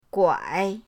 guai3.mp3